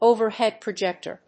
アクセントóverhead projéctor